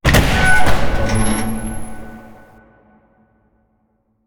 Apertura de la puerta de una nave espacial
Sonidos: Especiales
Sonidos: Fx web